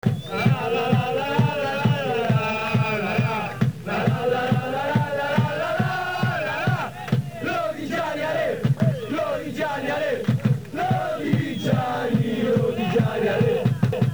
I cori in Mp3 degli Ultrà Lodigiani
Purtroppo l'acustica non è perfetta ma questo è quanto riusciamo a fare.
Partite varie in casa